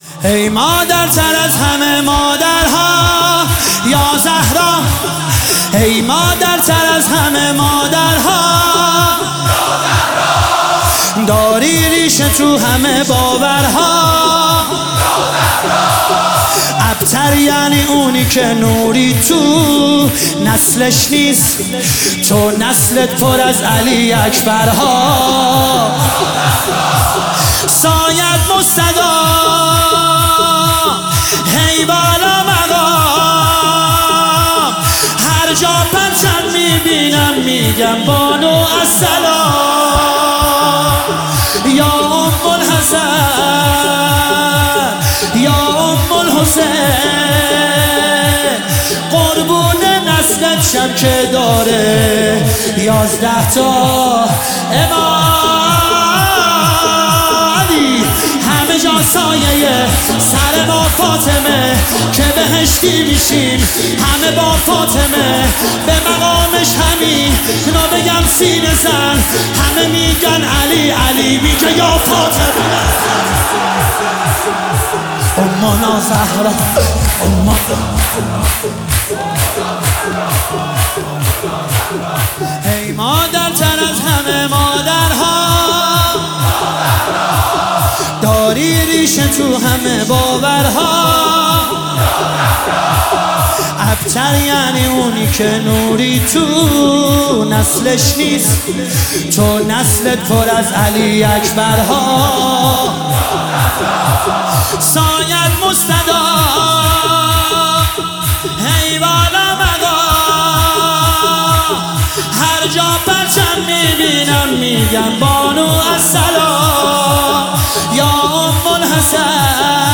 مداحی_شهادت حضرت زهرا